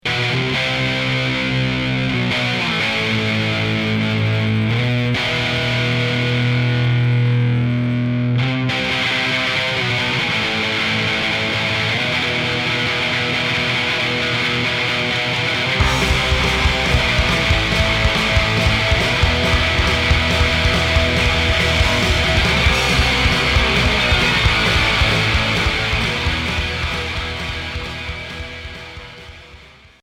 Rock hardcore